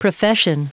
Prononciation du mot profession en anglais (fichier audio)
Prononciation du mot : profession